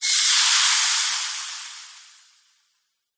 Звуки бытовые
Шипение раскаленного металла, мгновенная вспышка